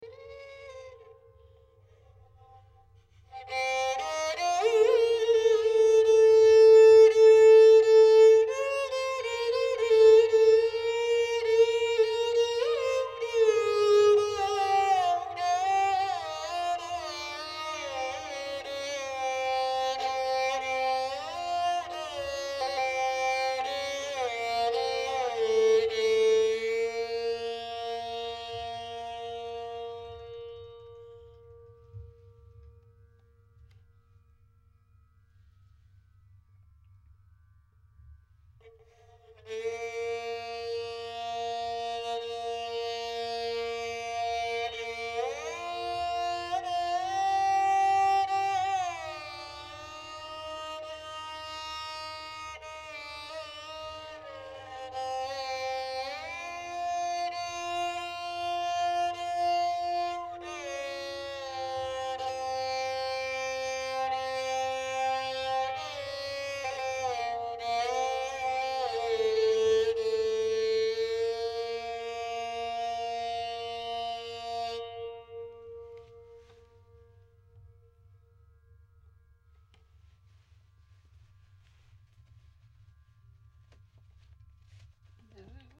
It is of Khamaj Thaat.
Few illustrations of Raag Maajh:
Dilruba 1:
dilruba_maajh1.mp3